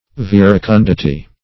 Search Result for " verecundity" : The Collaborative International Dictionary of English v.0.48: Verecundity \Ver`e*cun"di*ty\, n. The quality or state of being verecund; modesty.